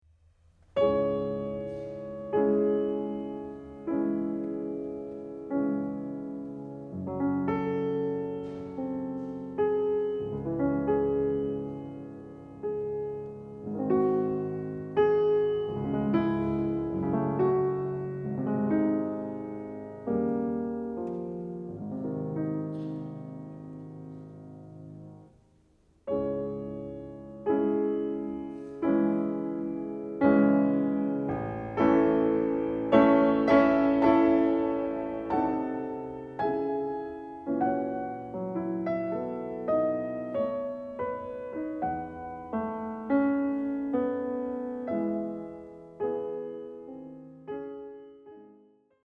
Here, for example, in Schumann’s Symphonic Etudes, the rhythmic structure is as “classic” as can be, but the melody is pure romantic music (meaning that the regularity of the phrase should not be emphasized by the performer and not strongly felt by the listener):